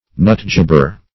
nutjobber - definition of nutjobber - synonyms, pronunciation, spelling from Free Dictionary Search Result for " nutjobber" : The Collaborative International Dictionary of English v.0.48: Nutjobber \Nut"job`ber\, n. (Zool.)